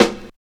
35 SNARE 4.wav